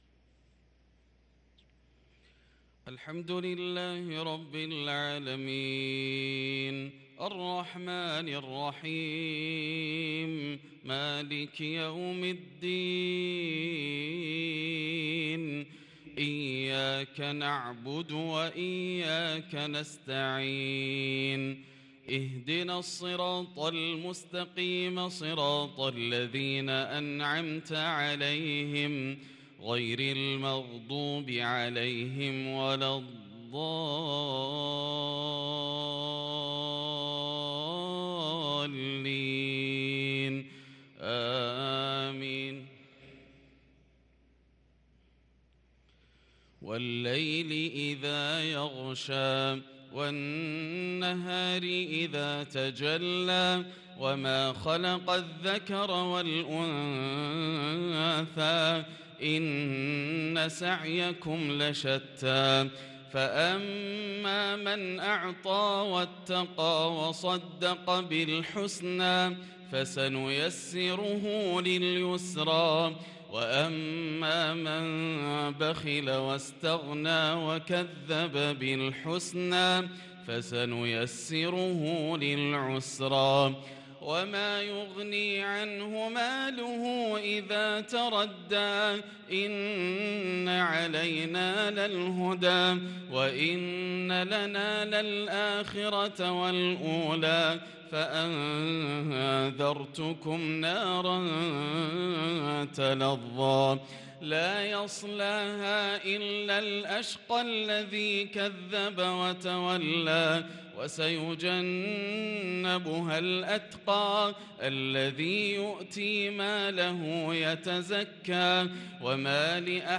صلاة المغرب للقارئ ياسر الدوسري 16 صفر 1444 هـ
تِلَاوَات الْحَرَمَيْن .